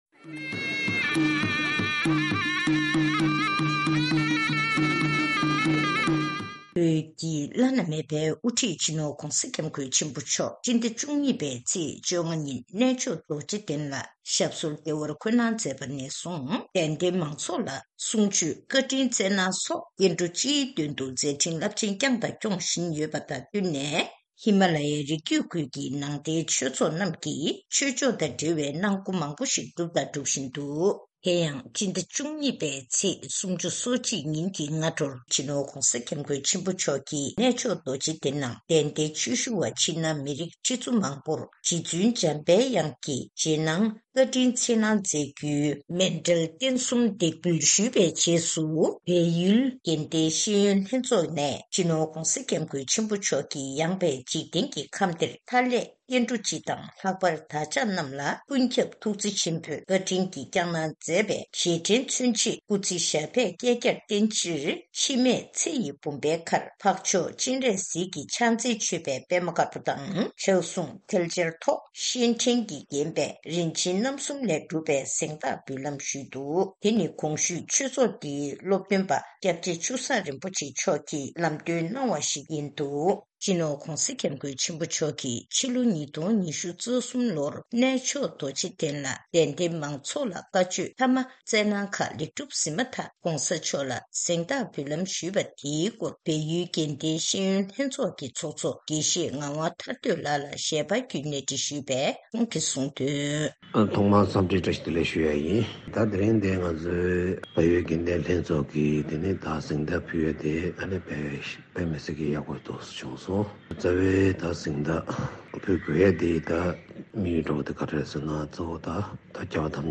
གནས་འདྲི་ཞུས་ནས་གནས་ཚུལ་ཕྱོགས་སྒྲིག་ཞུས་པ་ཞིག་གསན་རོགས་གནང་།